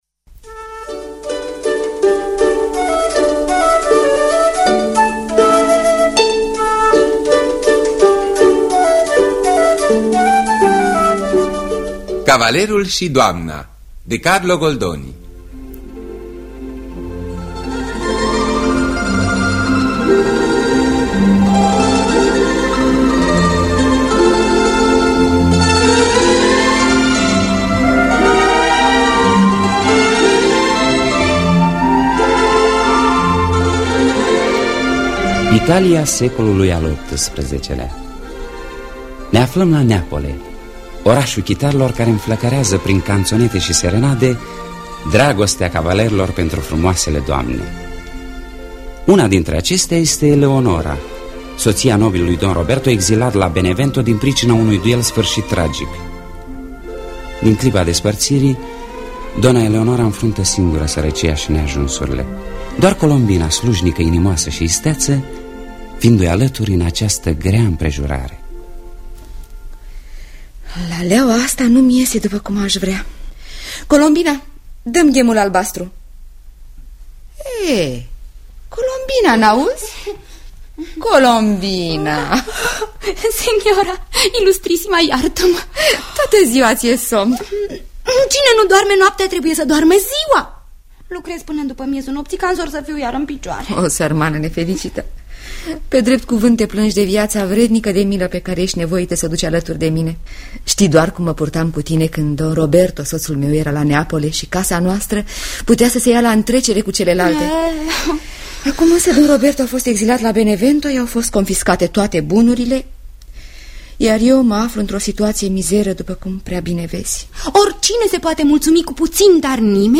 Adaptarea radiofonică
Înregistrare din anul 1975.